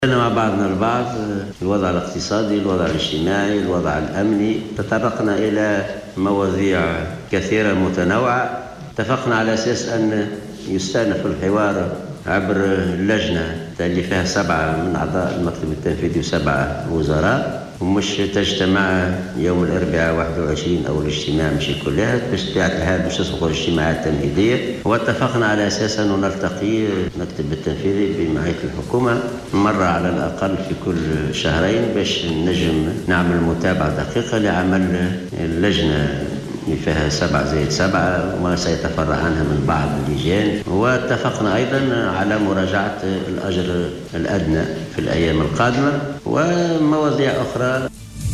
قال الأمين العام للإتحاد العام التونسي للشغل حسين العباسي في تصريح صحفي عقب لقاء جمع مساء اليوْم بقصر الحُكومة بالقصبة رئيسَ الحُكومة مهدي جمعة و أعضاء المكتب التنفيذي للاتحاد العام التونسي للشغل بحضُور عدد من الوُزراء إن تم الاتفاق على استئناف الحوار يوم الاربعاء 21 مارس الحالي وبعث لجنة 7 زائد 7 بين ممثلين عن الحكومة وعن المكتب التنفيذي .